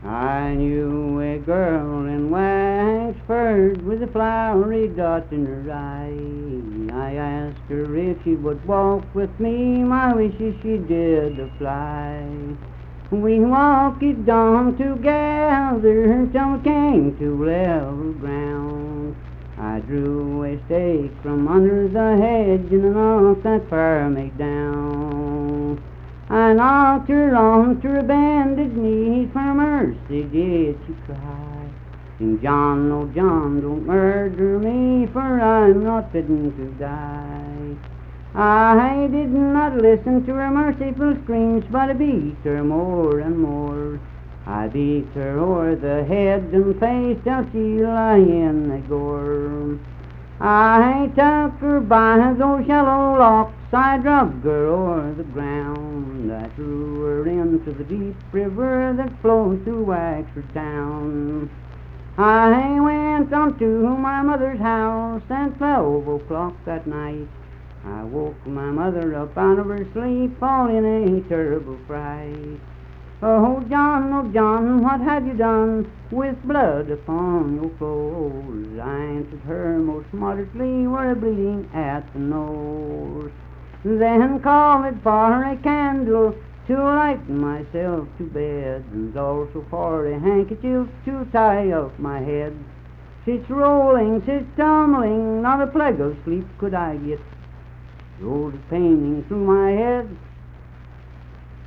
Unaccompanied vocal music
Verse-refrain.
Voice (sung)